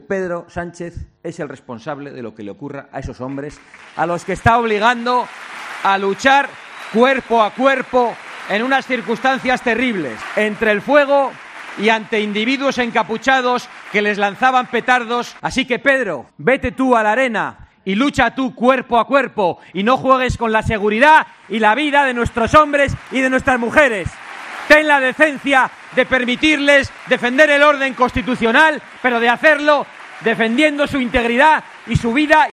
Escucha las palabras del presidente de VOX, Santiago Abascal
En su intervención en un acto público en el Palacio de Congresos de Córdoba, Abascal ha lamentado que "el Gobierno no habla con los que están trabajando a pie de obra, que no pueden utilizar material de antidisturbios para actuar contra una masa que lanza ácido" contra los agentes, que presentan "cortes en los brazos", además de "lanzarles adoquines".